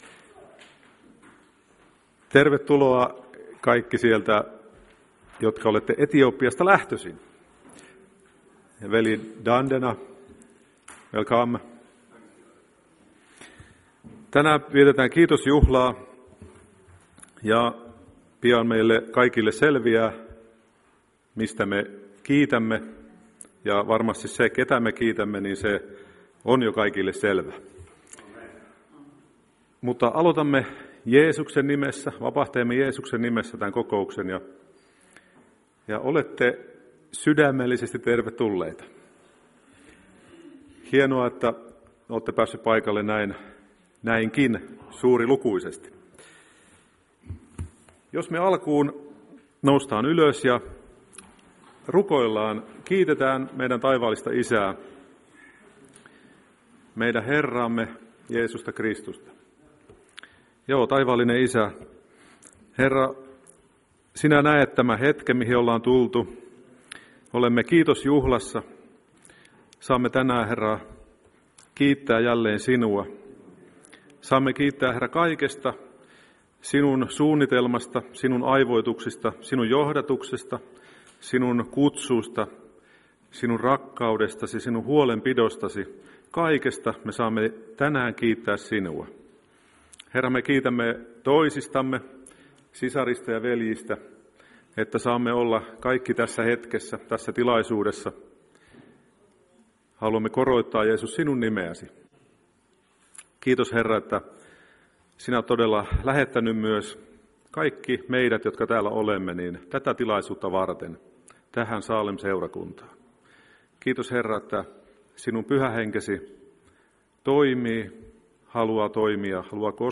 Kiitosjuhla 22.9.2024